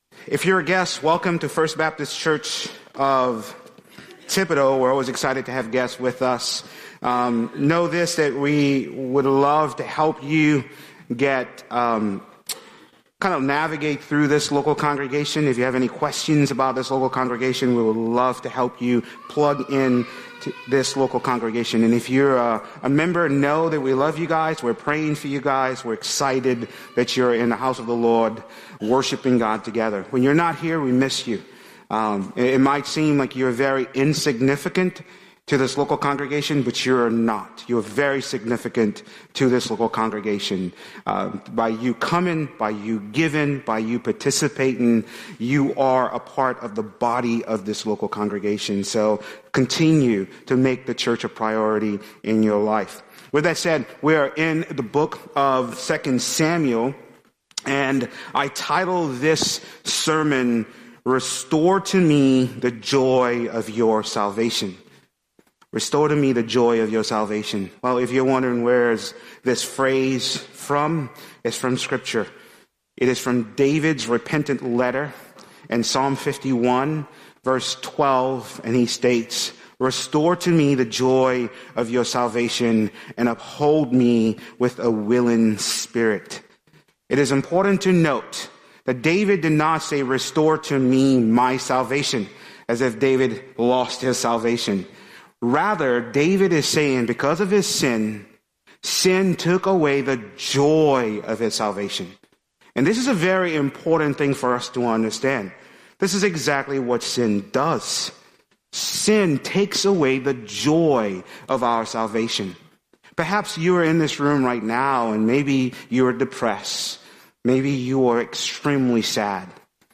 A message from the series "The Book of 2 Samuel."